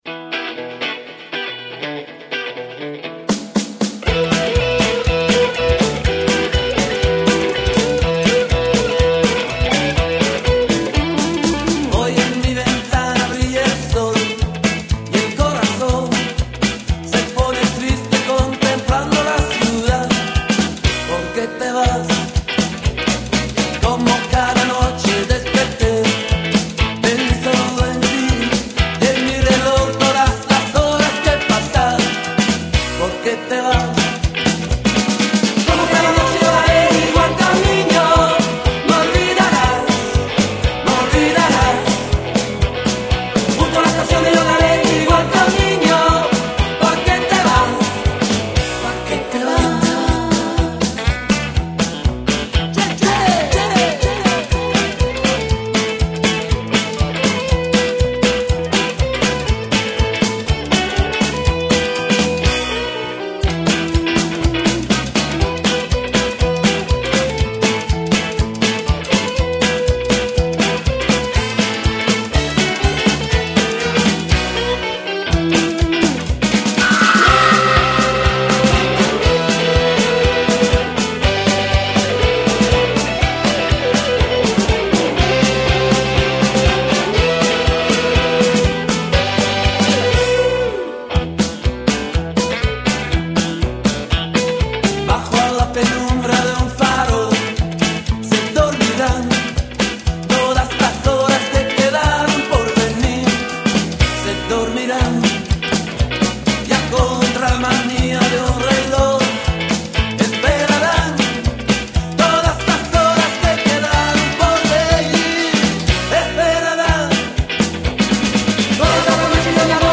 кантри